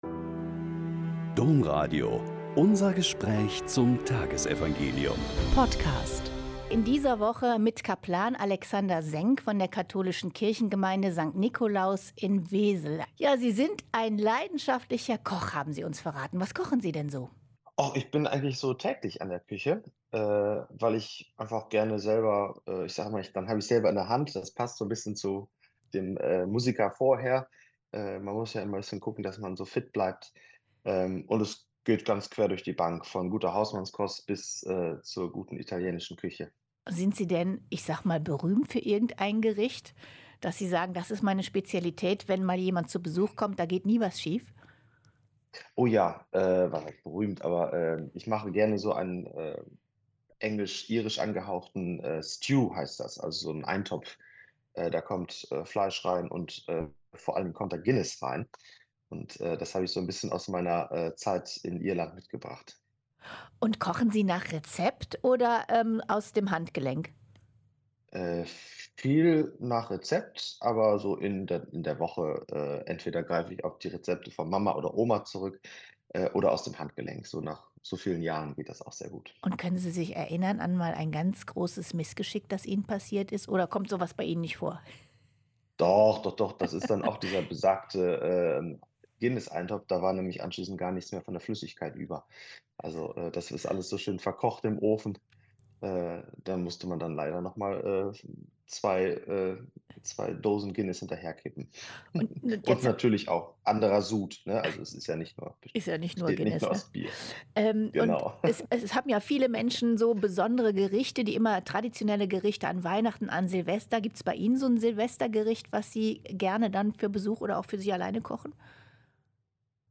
Mt 2,13-18 - Gespräch